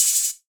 pbs - panned [ OpHat ].wav